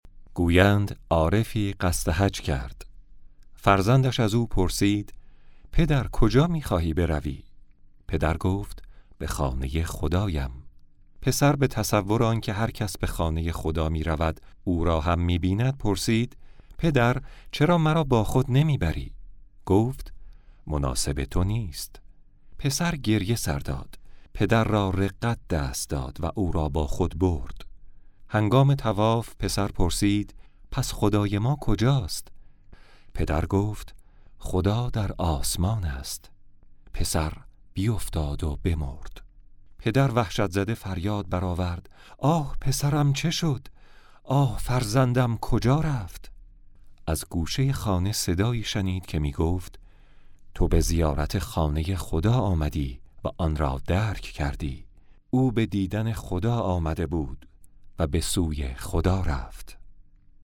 story.mp3